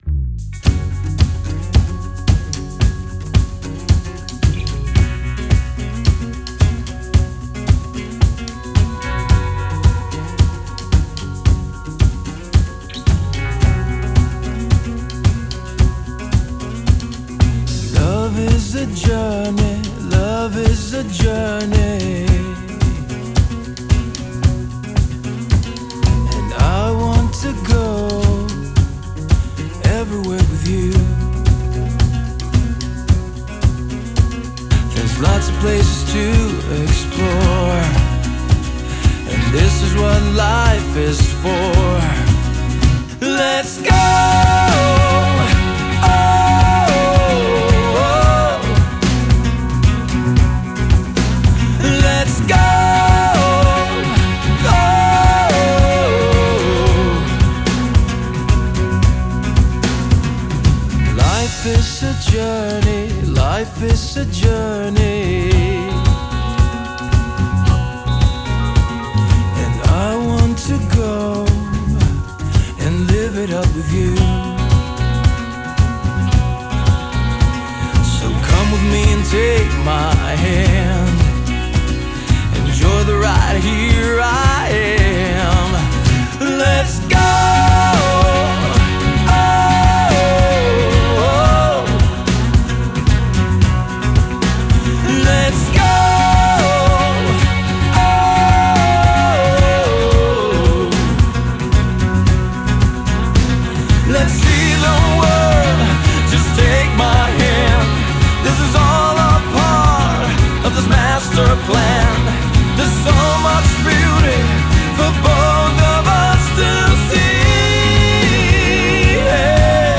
• Genre: Pop / Rock